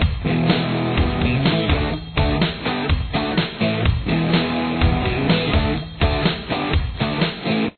The guitar mostly utilizes common chord shapes: